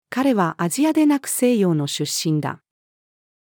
彼はアジアでなく西洋の出身だ。-female.mp3